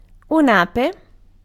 Ääntäminen
US : IPA : [bi]